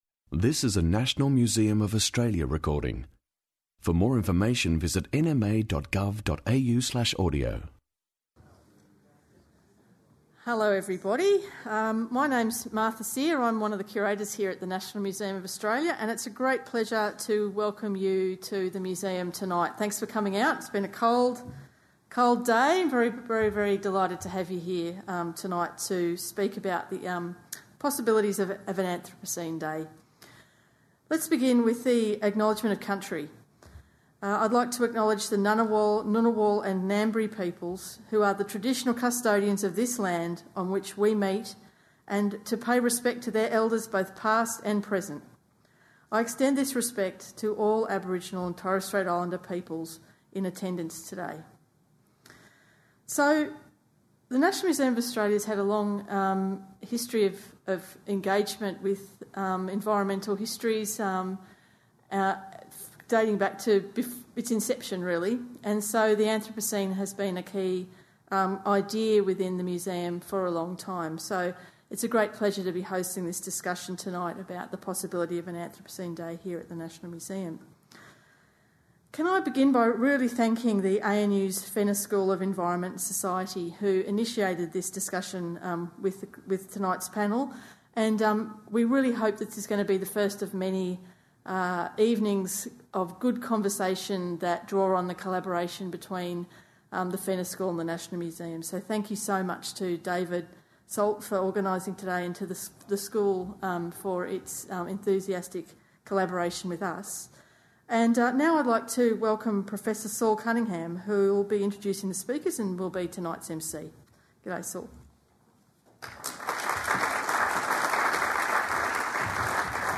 16 Jul 2018 Reflecting on Anthropocene Day Panellists discuss a proposed Anthropocene Day on 16 July to reflect on a new geological epoch in which humans now dominate the Earth system.